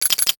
NOTIFICATION_Glass_02_mono.wav